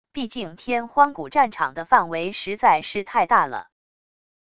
毕竟天荒古战场的范围实在是太大了wav音频生成系统WAV Audio Player